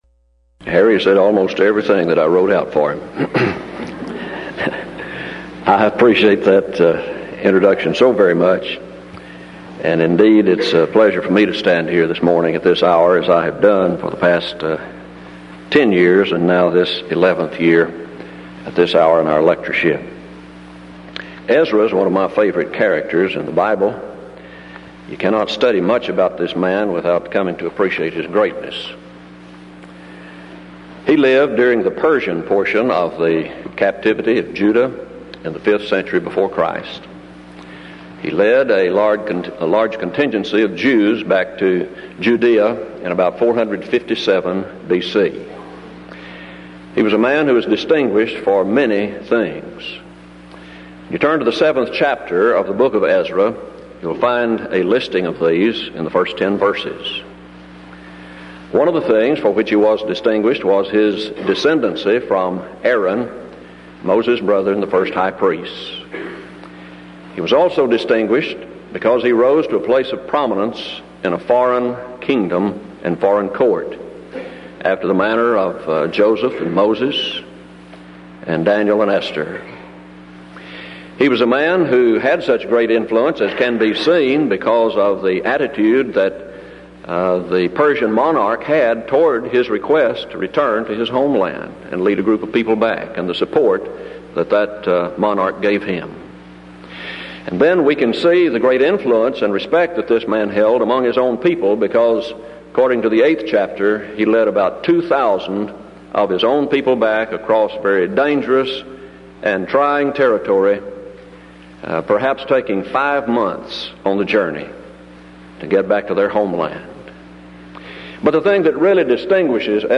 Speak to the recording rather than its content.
Event: 1992 Denton Lectures Theme/Title: Studies In Ezra, Nehemiah And Esther